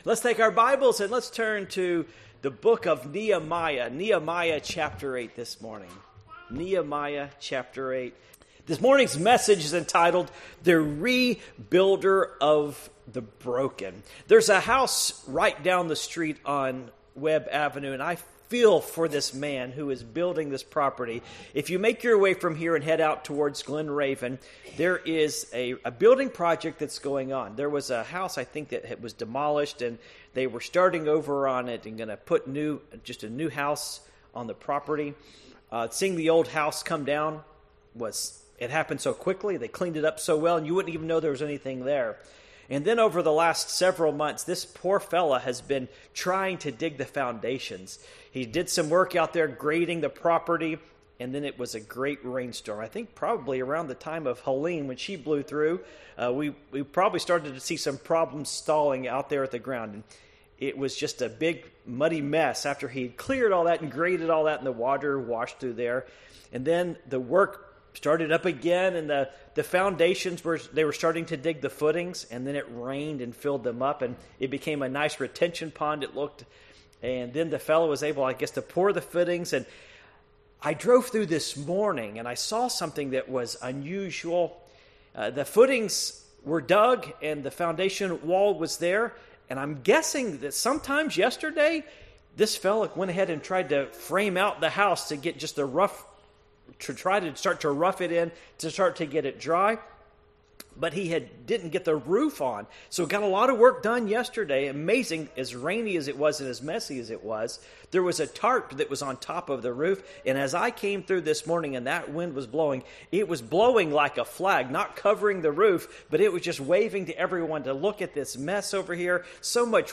Passage: Nehemiah 8:1-12 Service Type: Morning Worship